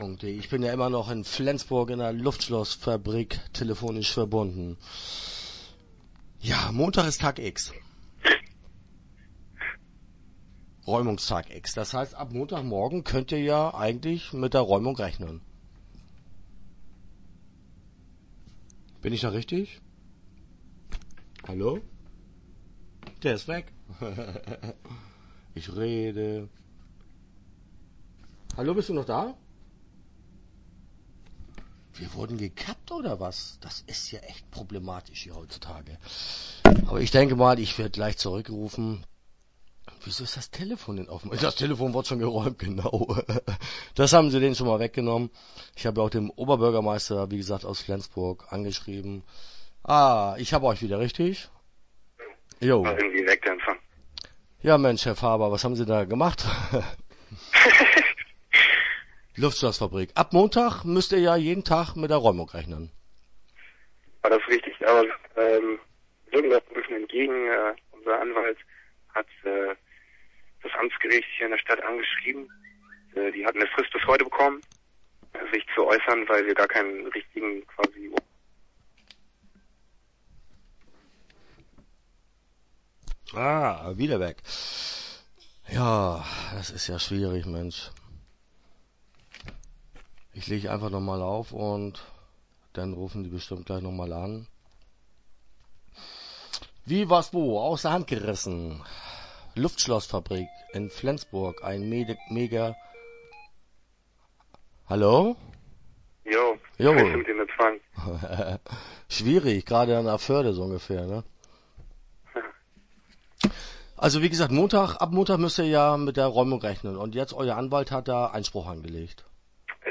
LuftschlossfAbrik - Interview Teil 1 (7:21)